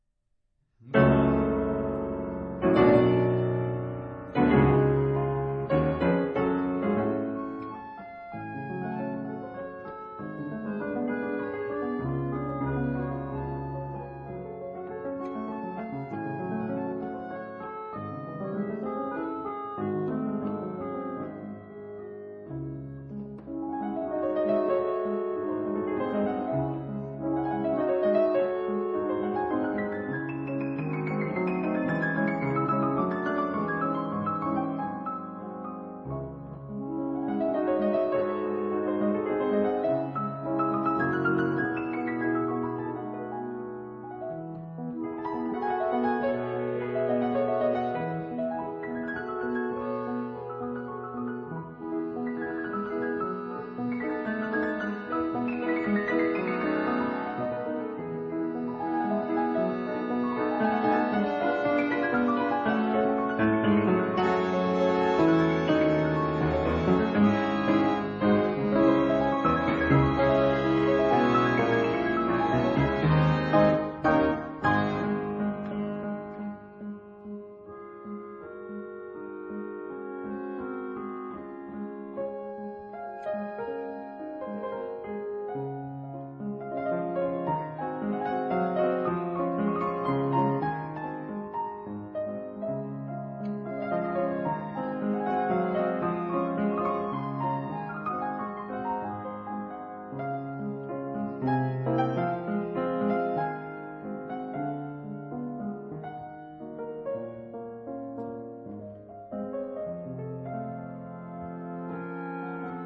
這是寫給簧風琴與鋼琴的曲子。
鋼琴提供節奏和精湛的細節，簧風琴則讓聲音有了廣度，
錄音使用的樂器包括一架1889年的架美麗簧風琴，
以及，聲音聽來高貴的1902年的Érard三角鋼琴。